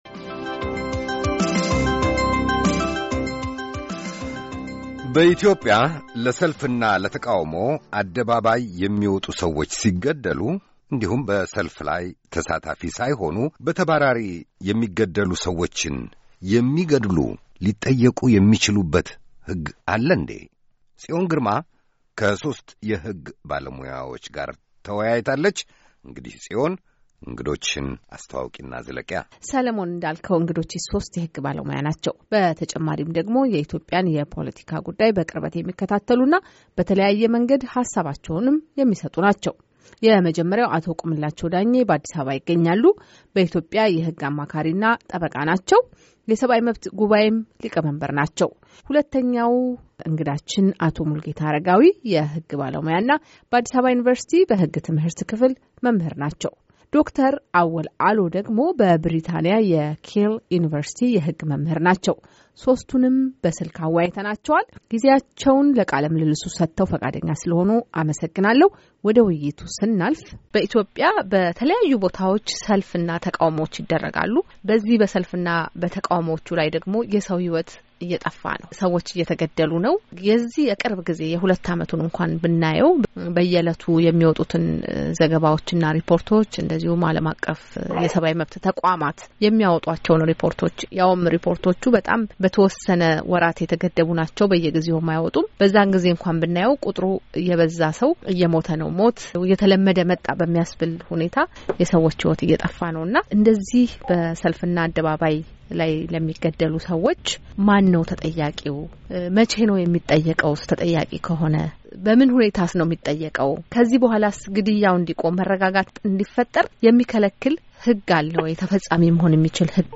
ሦስት የሕግ ባለሞያዎችን አነጋግራለች።